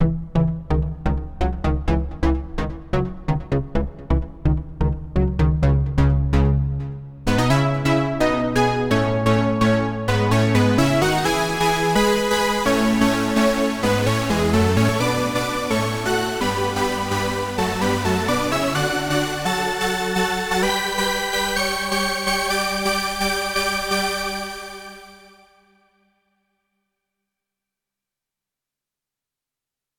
2 voice super saw
2-voice-super-saw.wav